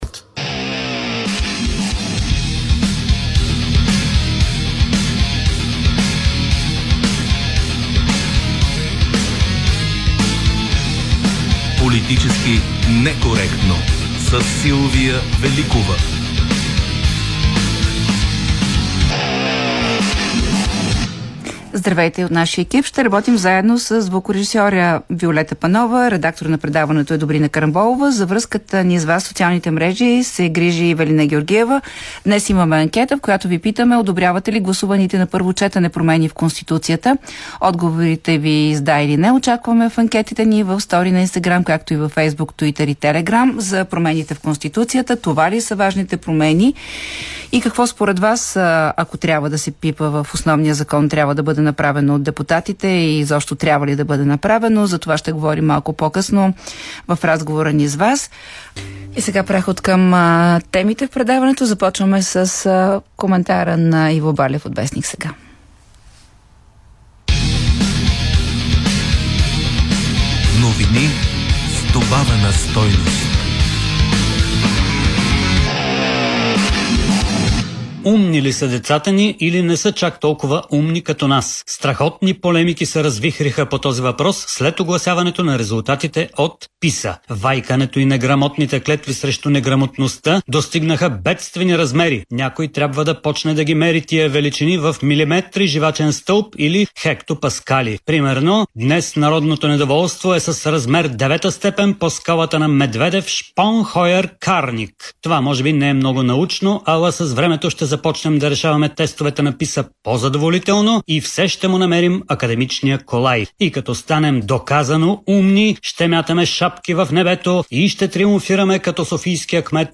▪ Гост е журналистът и бивш депутат от БСП Александър Симов.